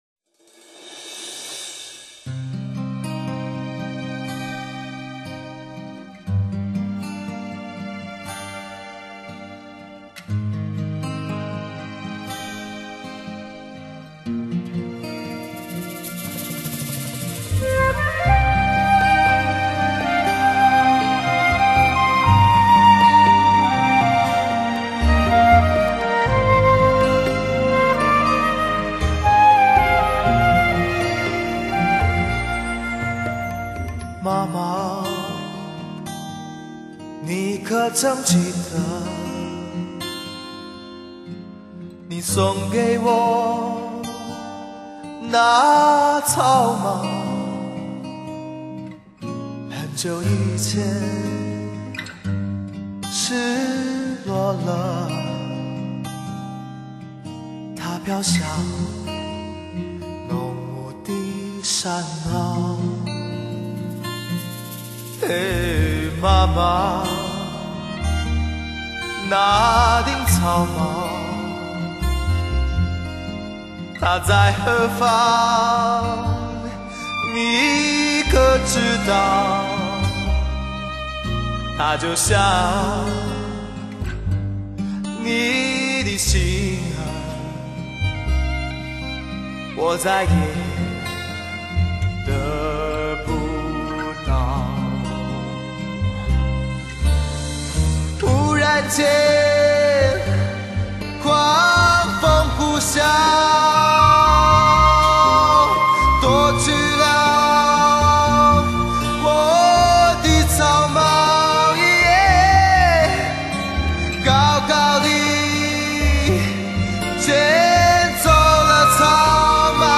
厚润感性嗓子 动听迷人的唱功 美妙的旋律 丰富的节奏